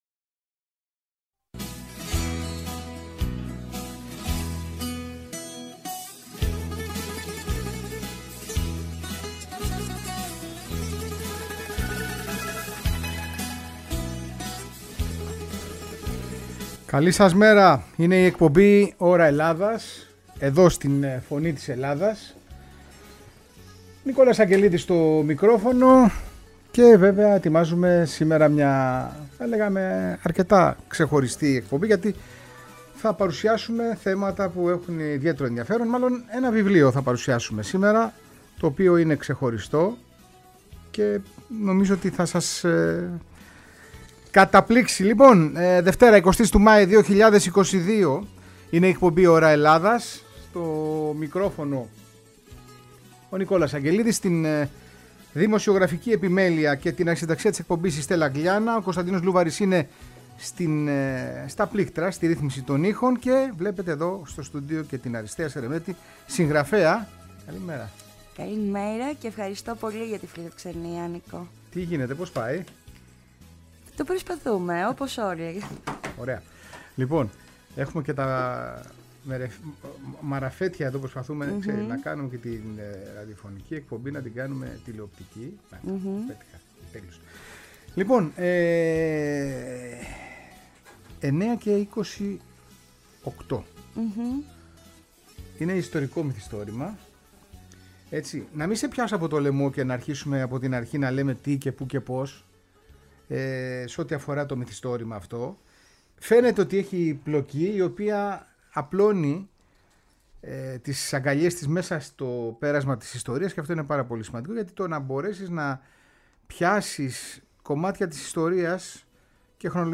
Η εκπομπή ΩΡΑ ΕΛΛΑΔΑΣ μεταδίδεται κάθε Δευτέρα και Τρίτη στις 9 το πρωί από τη συχνότητα της Φωνή της Ελλάδας, το παγκόσμιο ραδιόφωνο της ΕΡΤ.